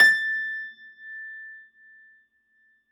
53f-pno21-A4.aif